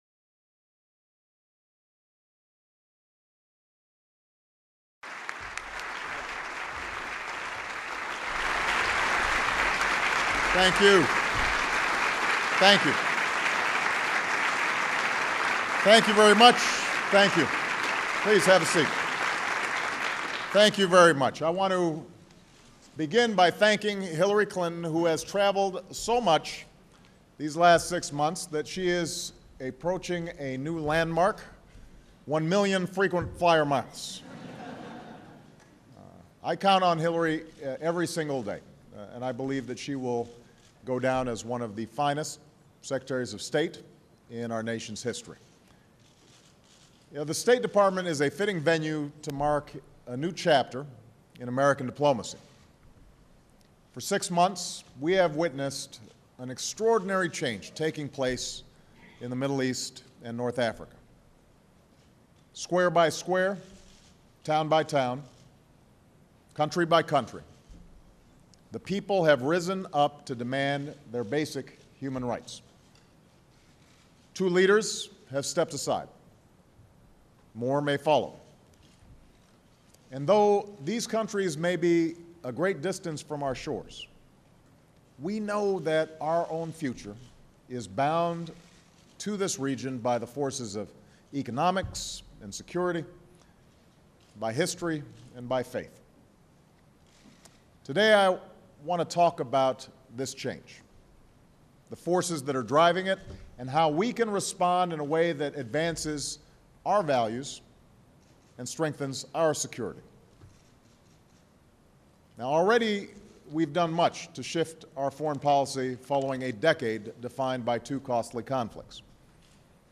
May 19, 2011: Speech on American Diplomacy in the Middle East and North Africa